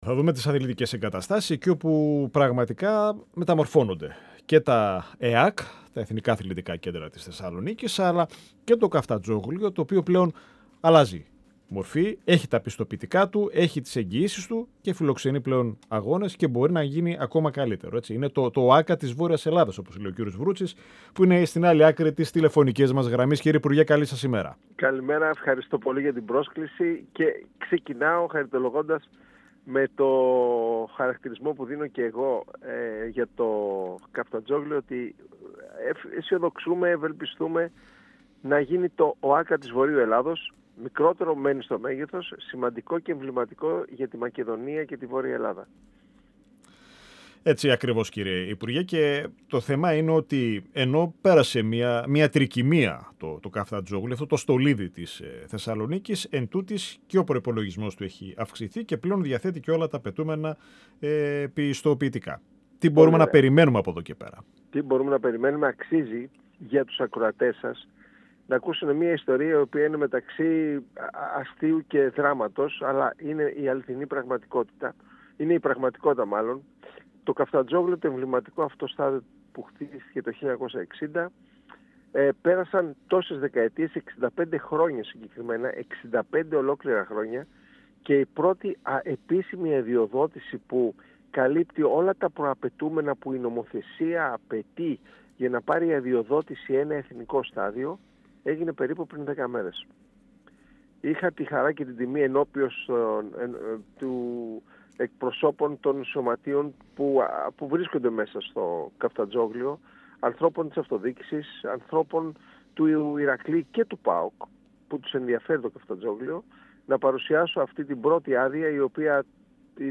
Ο αναπληρωτής υπουργός Αθλητισμού Γιάννης Βρούτσης, στον 102FM | «Ο Μεν και η Δε» | 16.10.2025